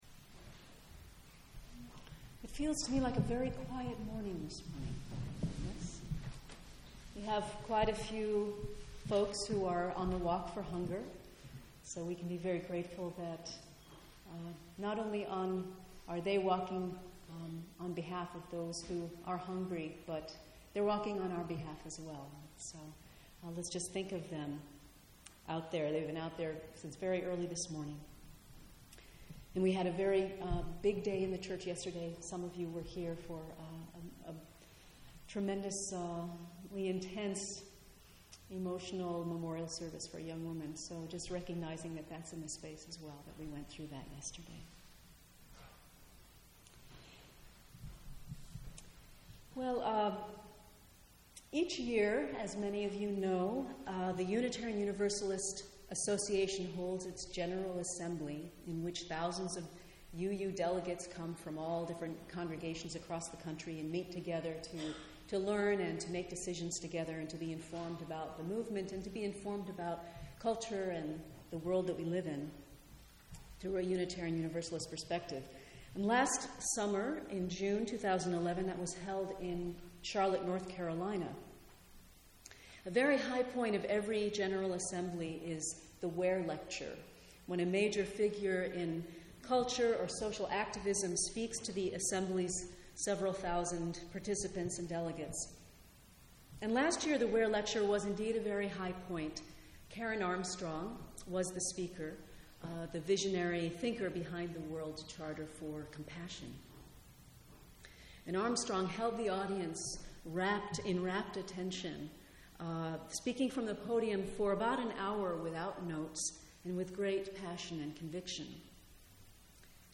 This sermon will explore how can we keep compassion as a dynamic force in our personal lives when we may feel both too much and too little.